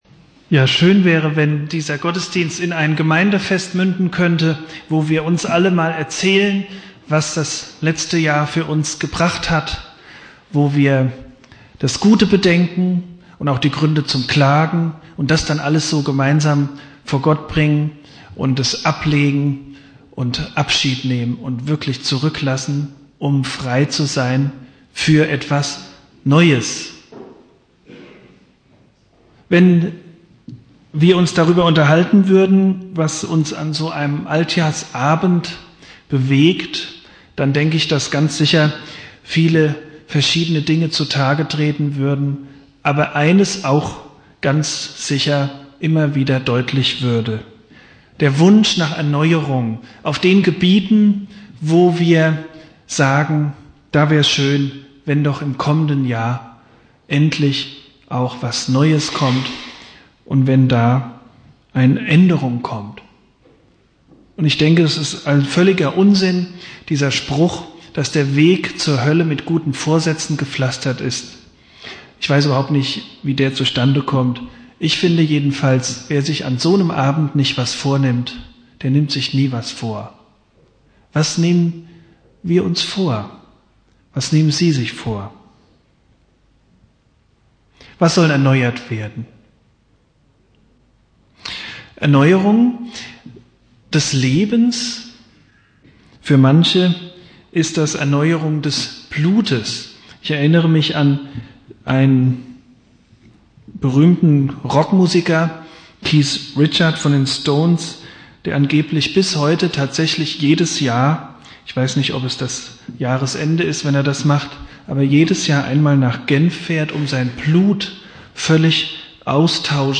Predigt
Silvester Prediger